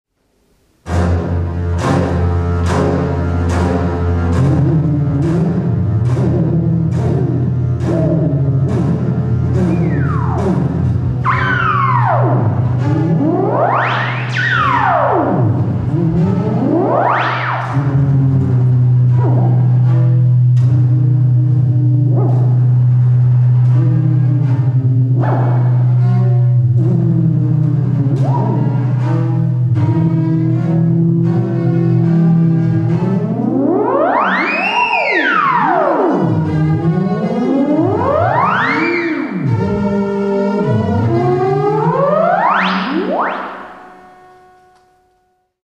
viol.MP3